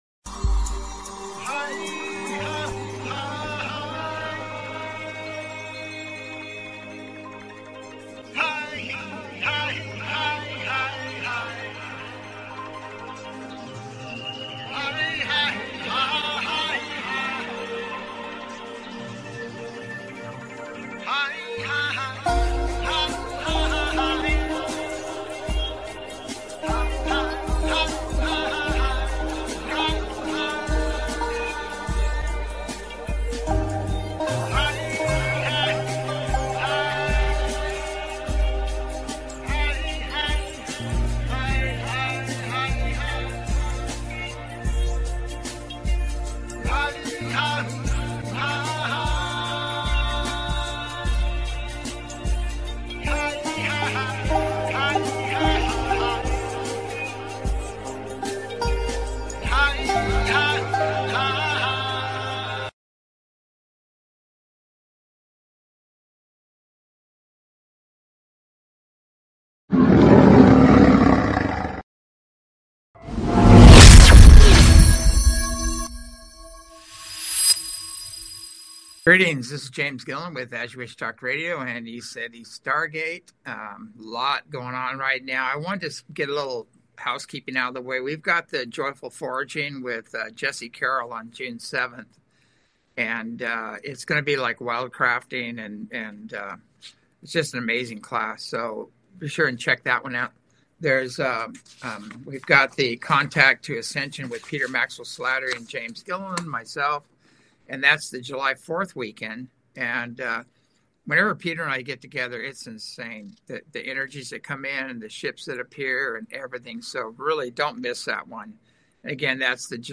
Talk Show Episode, Audio Podcast, As You Wish Talk Radio and Motherships Scout Ships, Time Folds, Karmic Backlash on , show guests , about Motherships Scout Ships,Time Folds,Karmic Backlash, categorized as Earth & Space,News,Paranormal,UFOs,Philosophy,Politics & Government,Science,Spiritual,Theory & Conspiracy